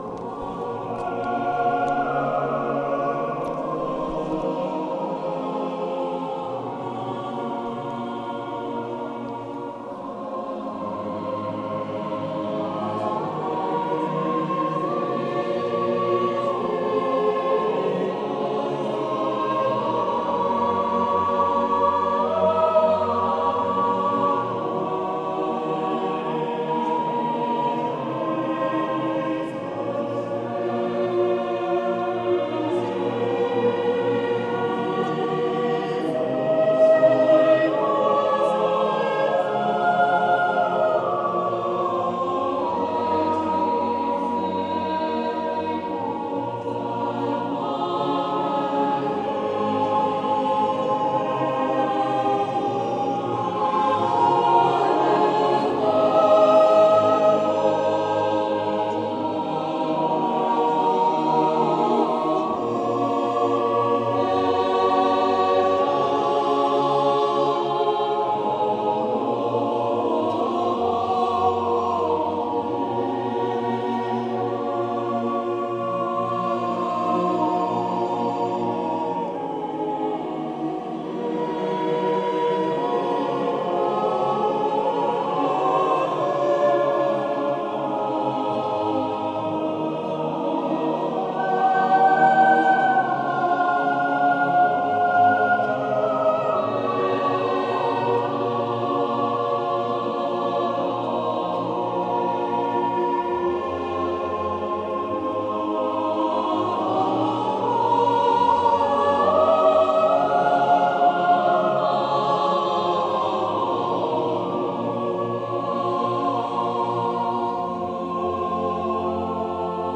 Choir in duomo 2